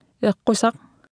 Speech Synthesis Martha